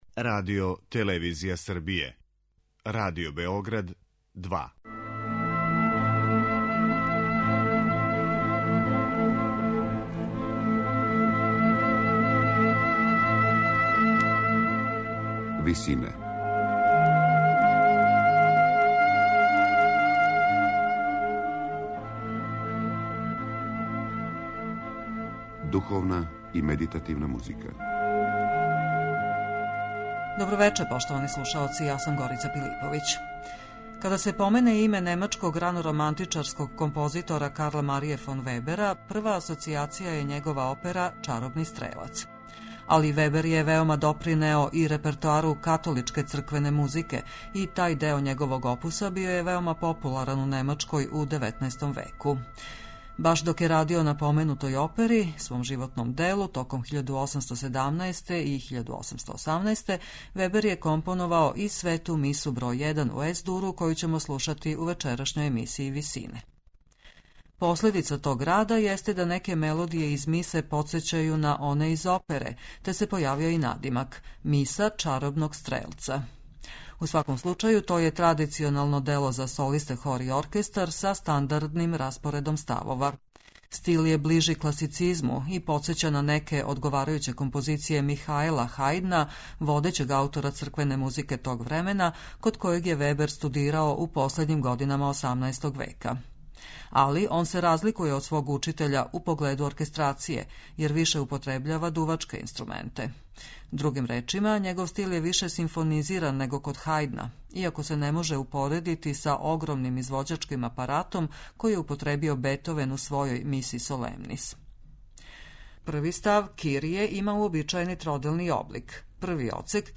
У сваком случају, то је традиционално дело за солисте, хор и оркестар, са стандардним распоредом ставова. Стил је ближи класицизму и подсећа на неке одговарајуће композиције Михаела Хајдна, водећег аутора црквене музике тог времена, код којег је Вебер студирао у последњим годинама XVIII века.